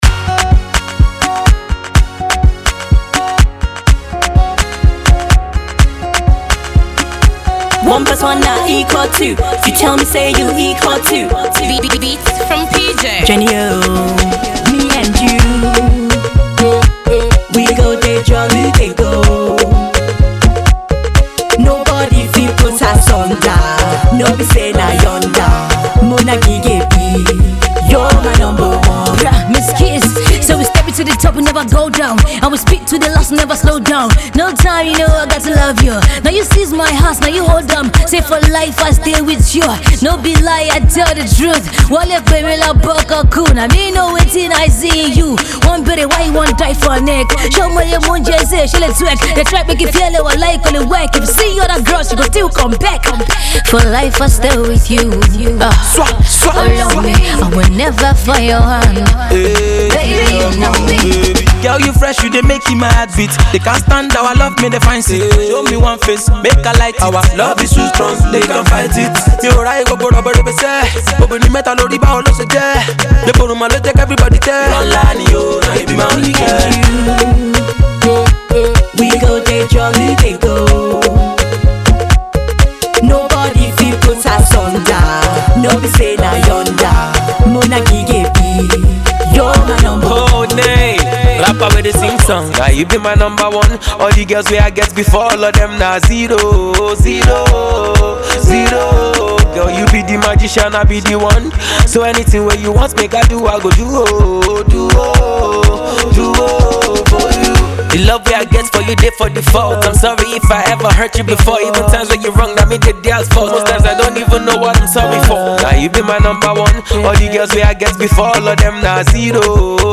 wavy jam